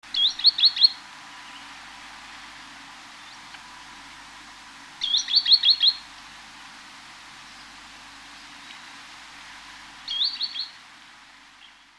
Birds Sounds Archive